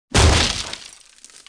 Broken.wav